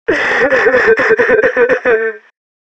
NPC_Creatures_Vocalisations_Robothead [95].wav